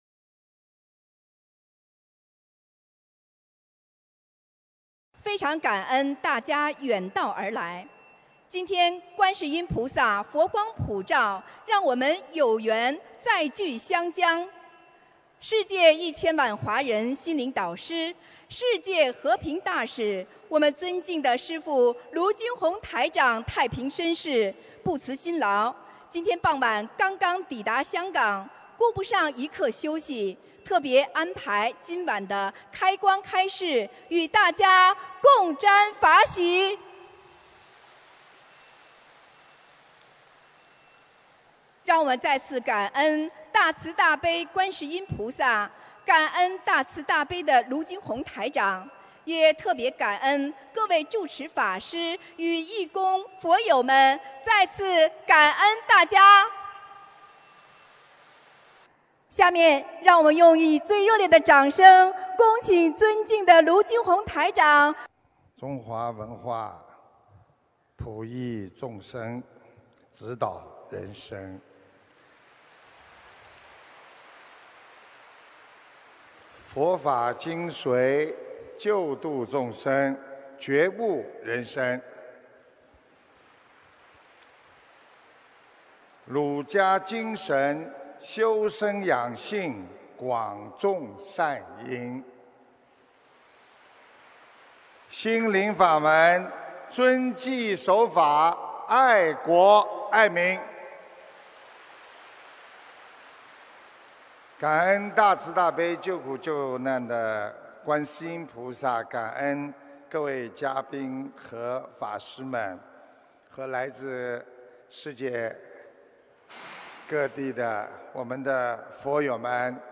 2016年7月1日中国香港见面会开示（视音文图） - 2016年 - 心如菩提 - Powered by Discuz!